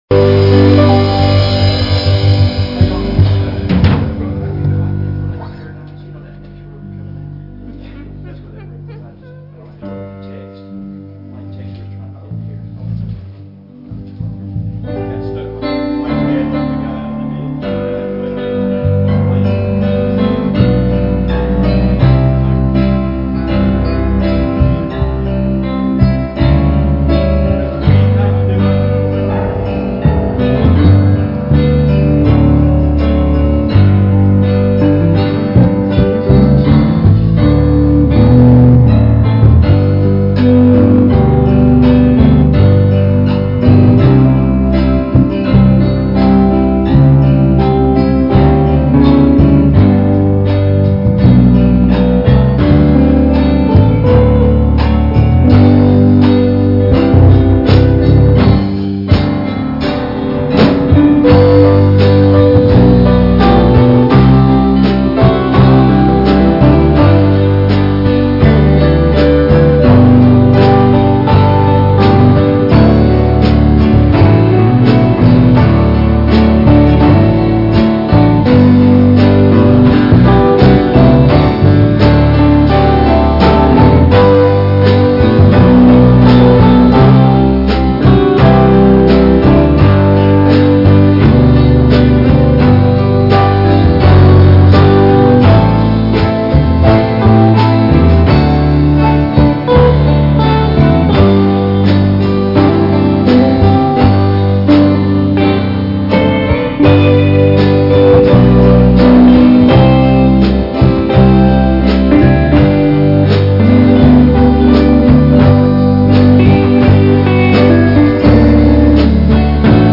Night of Singing